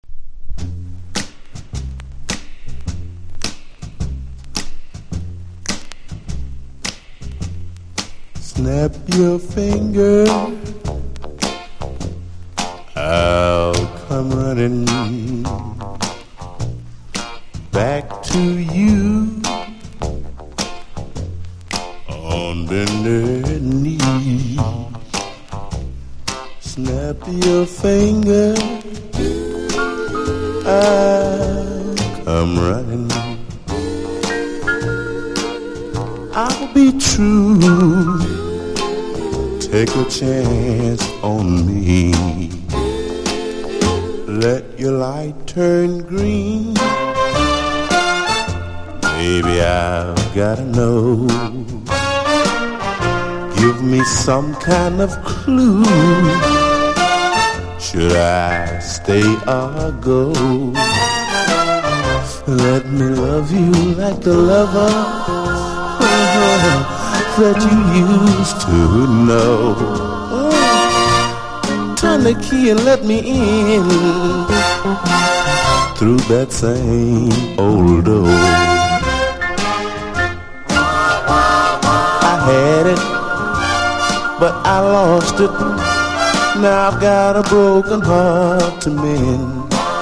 サム・クック・スタイルに歌うアーリー・ソウルの名作！！